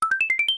Sound effect from Super Mario RPG: Legend of the Seven Stars
SMRPG_SFX_1-Up.mp3